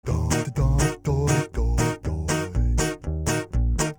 На гитаре